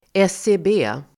Ladda ner uttalet
SCB förkortning, Uttal: [eseb'e:] Definition: Statistiska centralbyrånFörklaring: Central förvaltningsmyndighet för den officiella statistiken och för annan statlig statistik